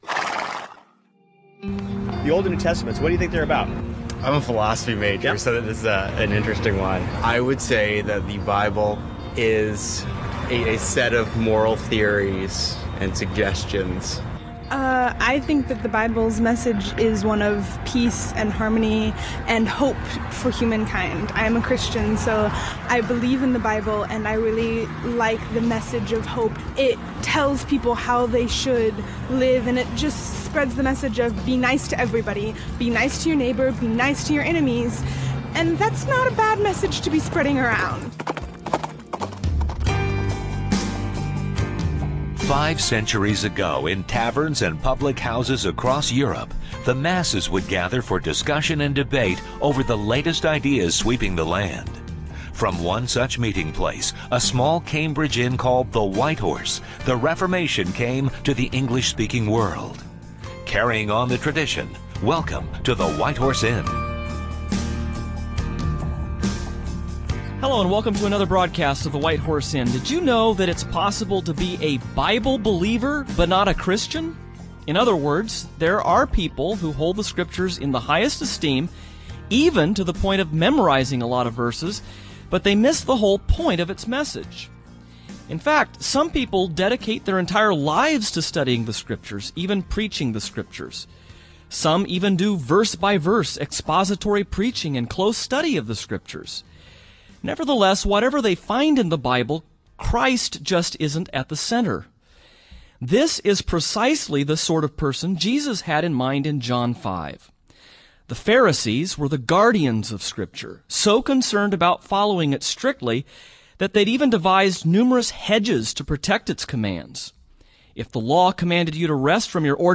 On this edition of the White Horse Inn, the hosts lay out their case for Christ as the primary subject of all Scripture, particularly in the Old Testament Law.